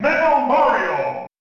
Category:Announcer calls (SSB)
Metal_Mario_Announcer_SSB.wav